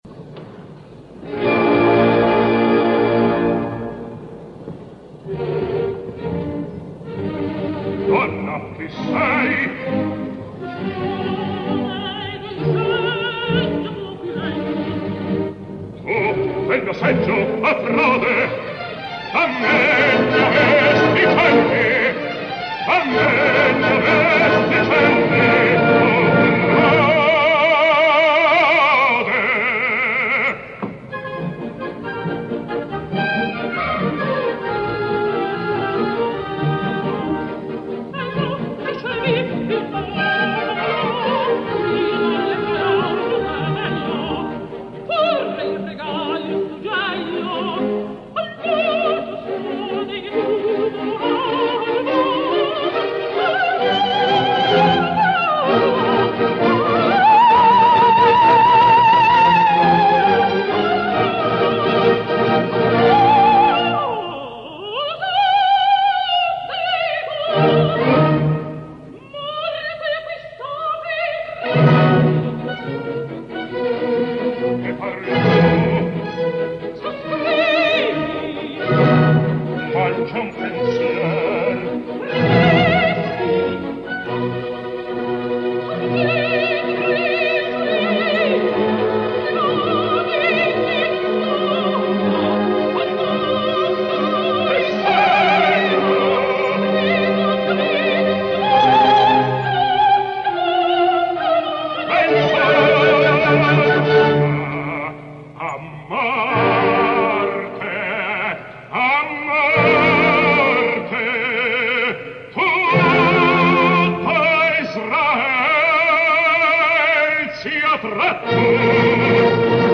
Orchestra del Maggio musicale Fiorentinodiretta da Bruno Bartoletti.